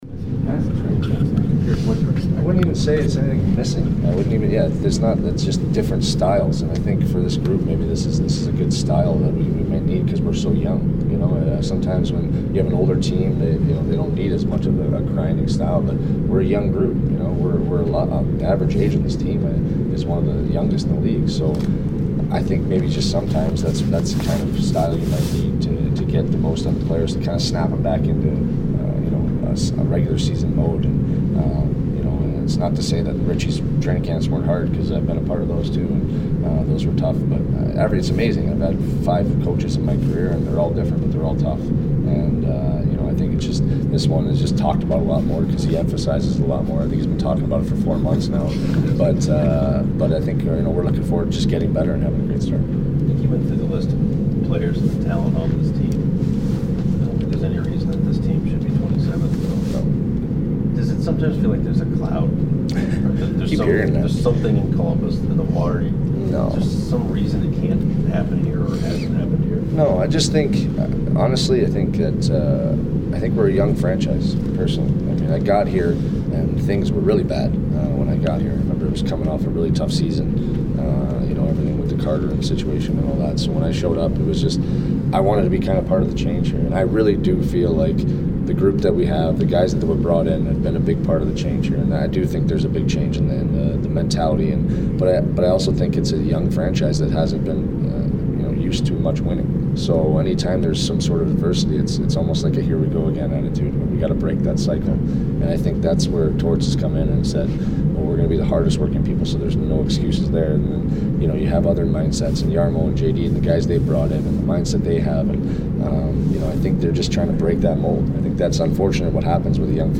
2016 Exit Interviews - Foligno